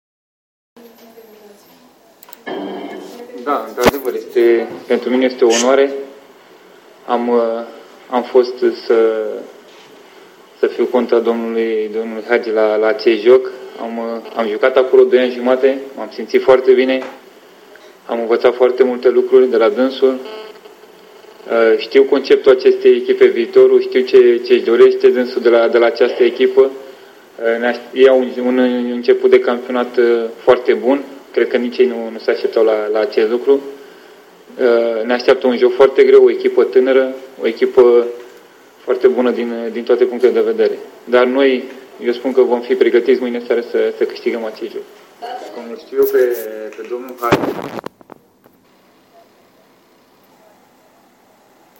Antrenorul secund al Stelei, Nicolae Dica